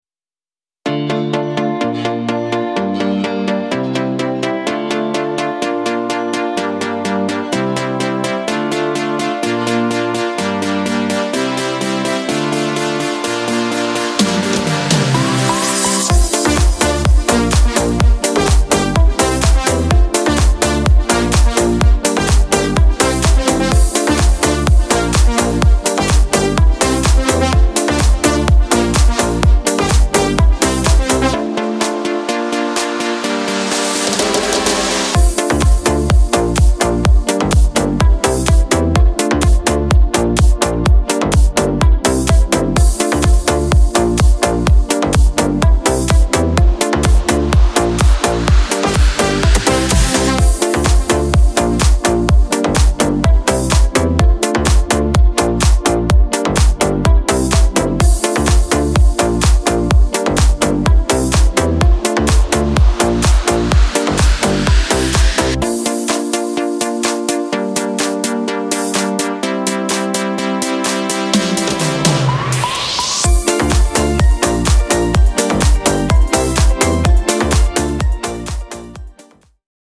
w tanecznej stylistyce i klubowym "bicie"
Disco Polo